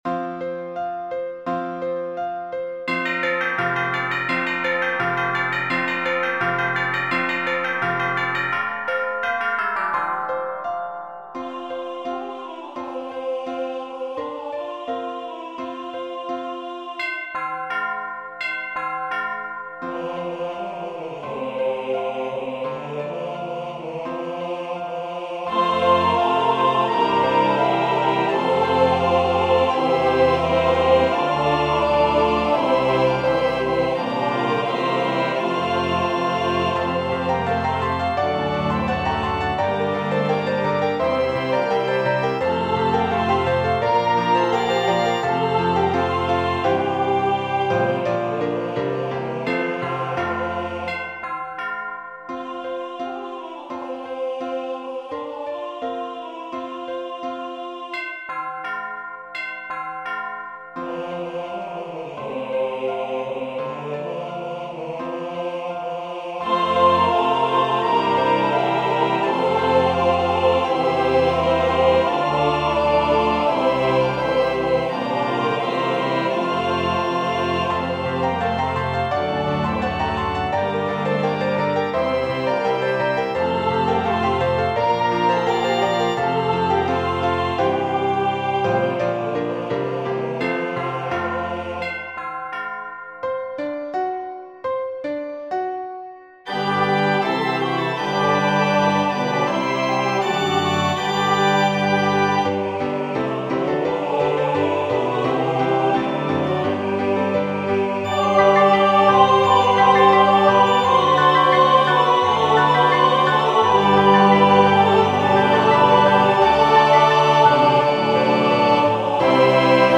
Voicing/Instrumentation: SATB , Organ/Organ Accompaniment , Piano Solo , Tubular Bells/Chimes We also have other 3 arrangements of " Ding Dong Merrily On High ".